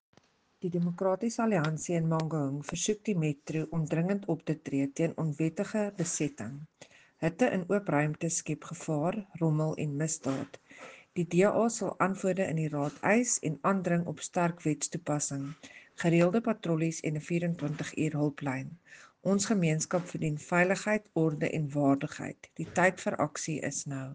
Afrikaans soundbites by Cllr Selmé Pretorius and